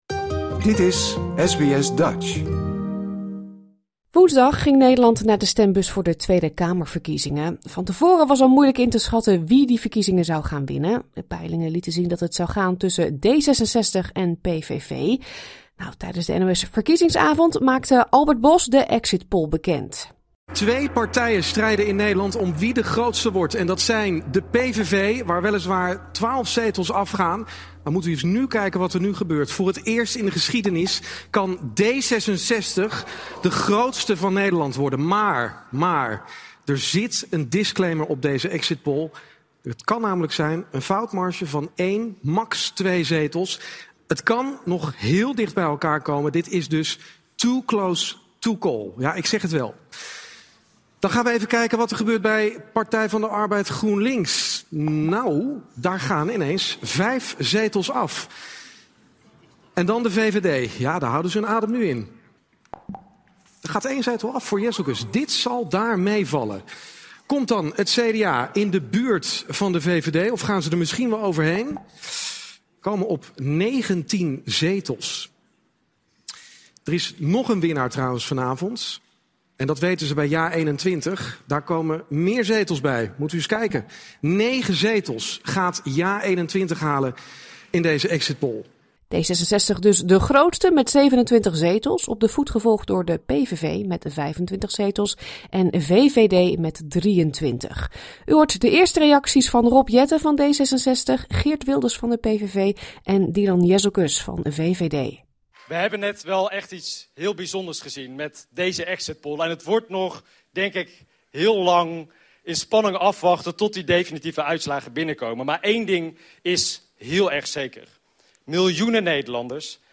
De audiofragmenten in deze bijdrage zijn afkomstig van de NOS. Mis niets van SBS Dutch!